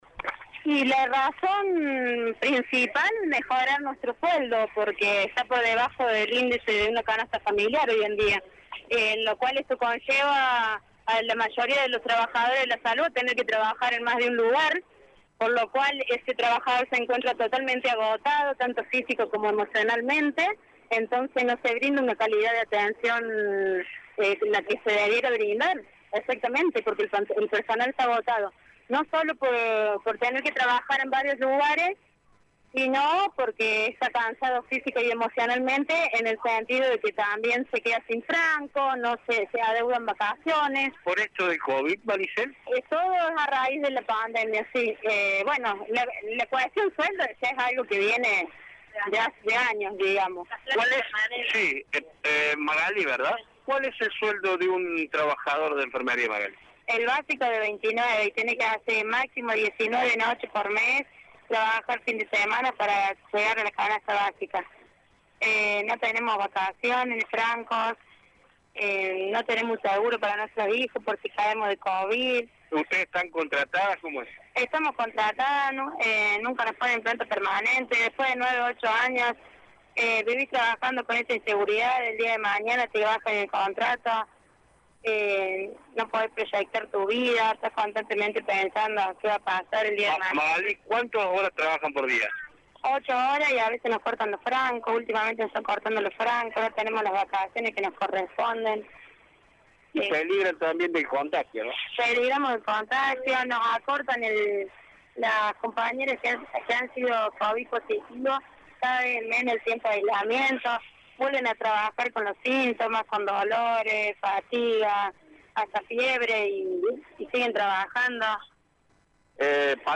Esto decían en diálogo con nuestro medio.